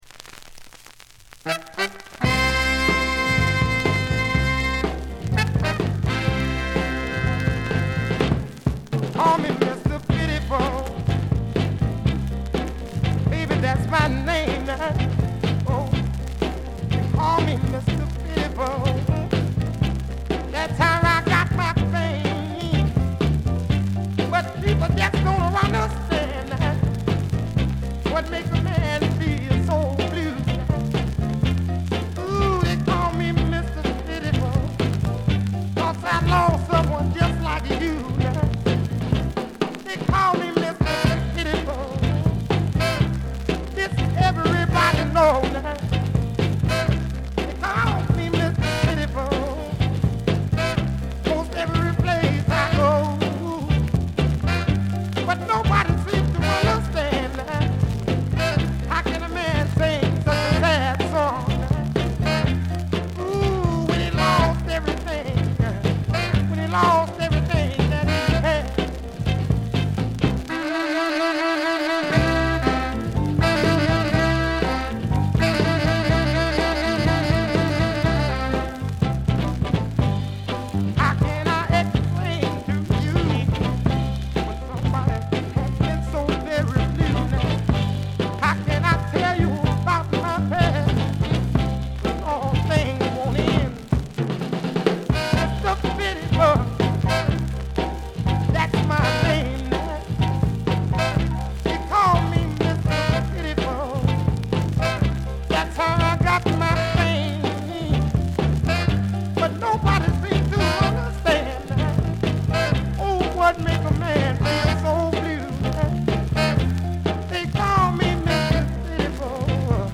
全編通じて強めのバックグラウンドノイズが出ます。
音質はよくありませんがコレクターの方はお見逃しなく。
試聴曲は現品からの取り込み音源です。
vocals